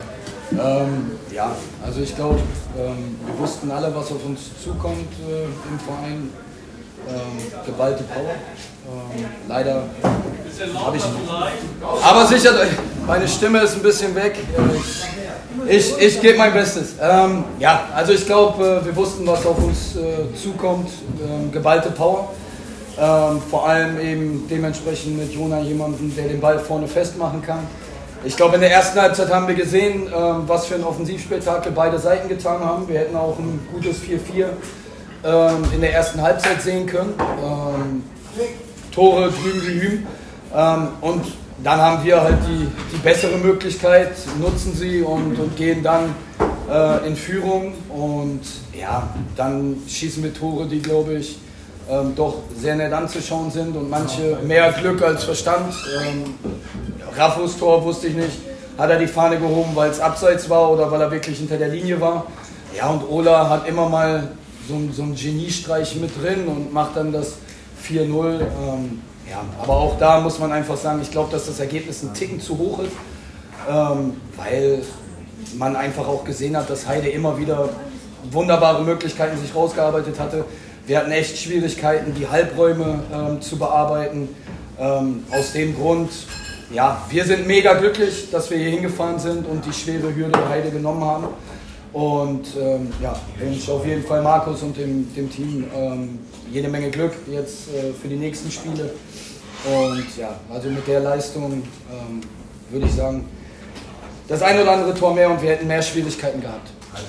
Stimmen zum Spiel